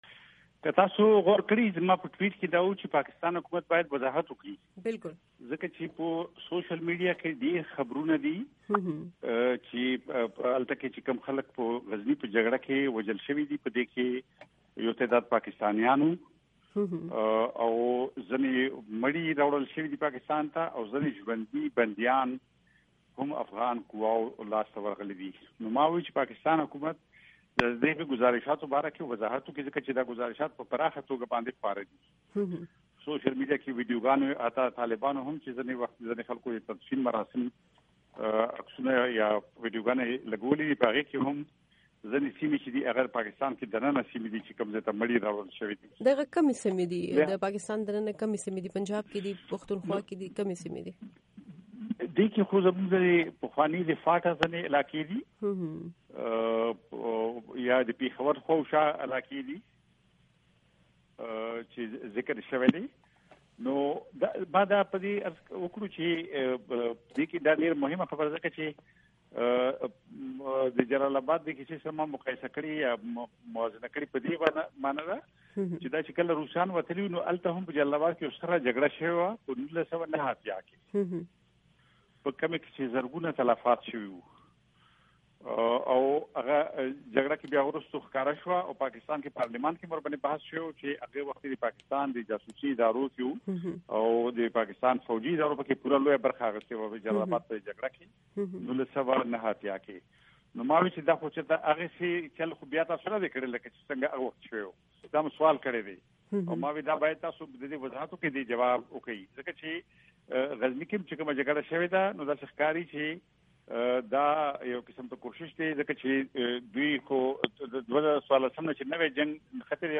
د افراسیاب خټک مرکه